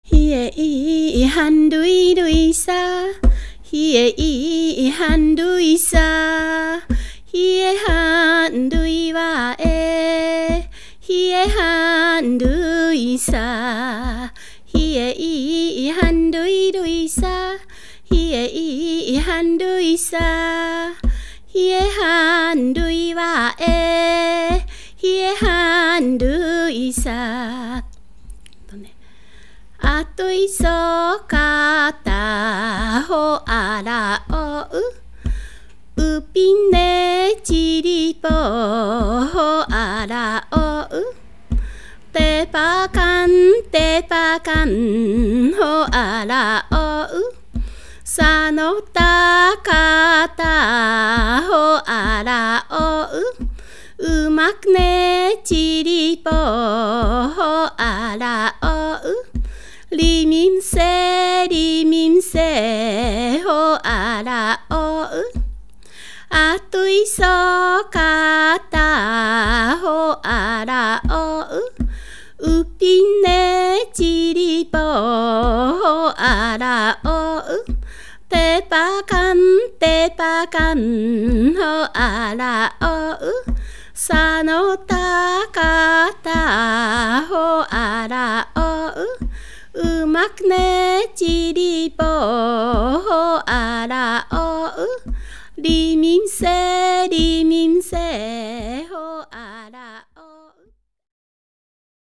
Canto tradicional ainu